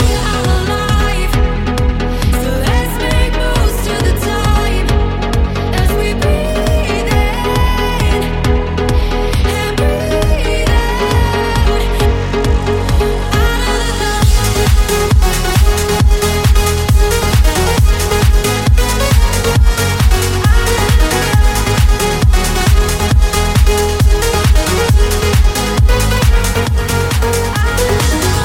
Genere: pop,dance,deep,disco,house.groove,latin,hit